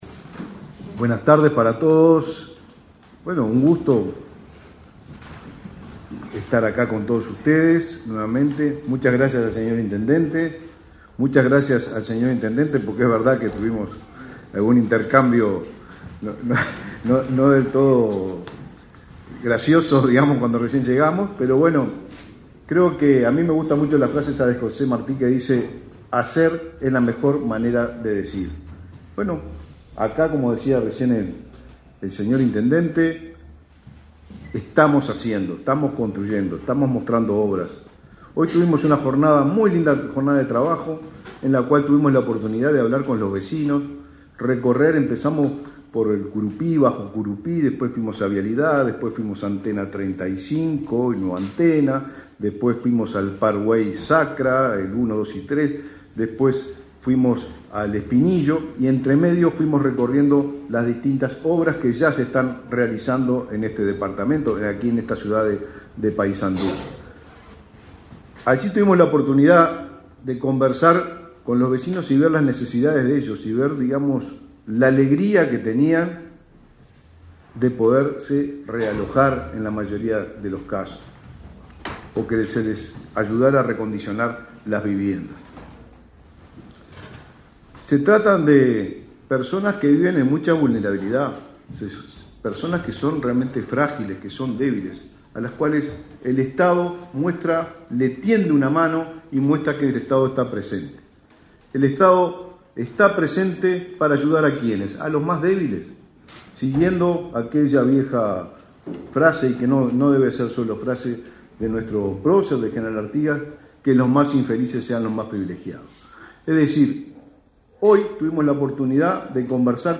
Conferencia de prensa para presentar intervenciones del plan Avanzar en Paysandú
Más tarde, los jerarcas realizaron una conferencia de prensa para presentar la hoja de ruta del plan en ese departamento.